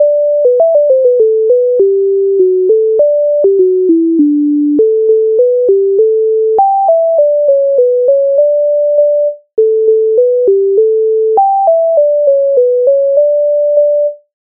MIDI файл завантажено в тональності G-dur
Калино малино Українська народна пісня